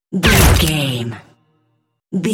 Dramatic shot hit ricochet
Sound Effects
heavy
intense
dark
aggressive